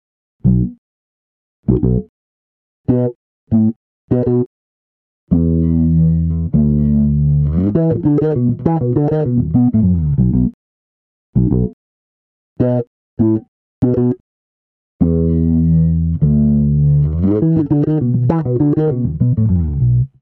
Bassriff-Quiz